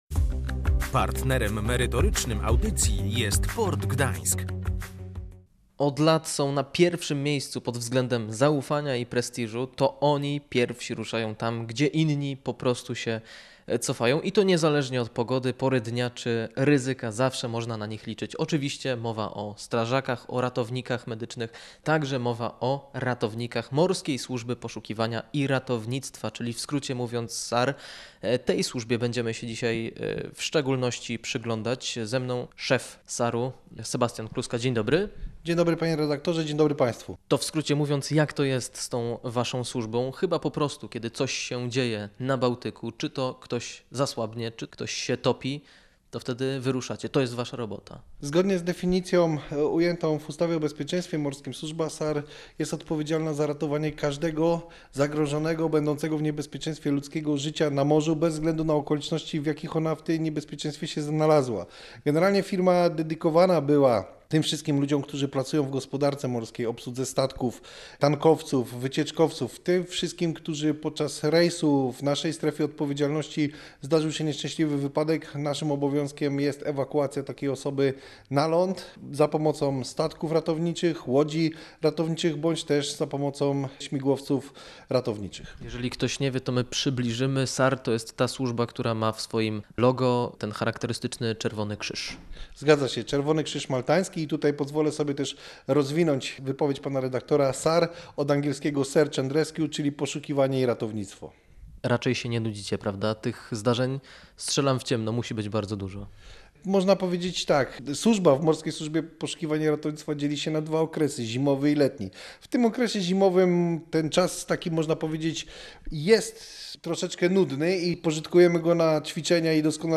Odwiedziliśmy gdyńską siedzibę SAR